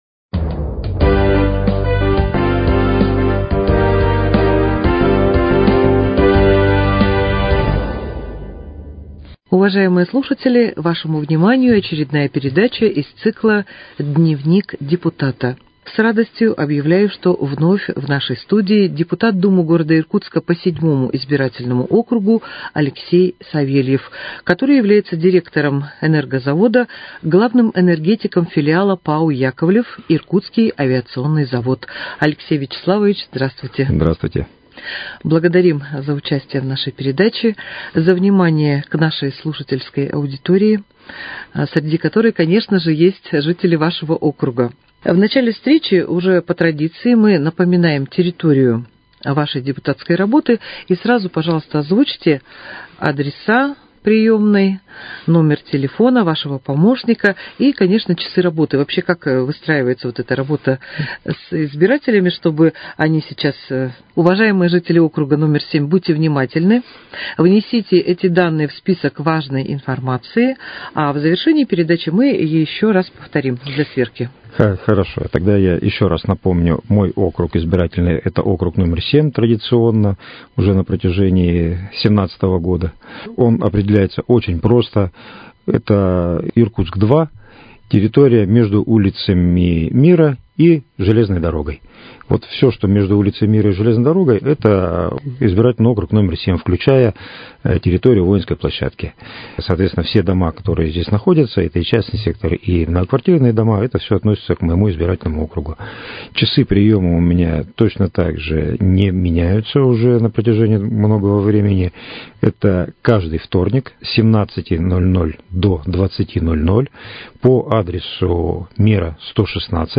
В передаче принимает участие депутат Думы г.Иркутска по округу № 7 Алексей Савельев.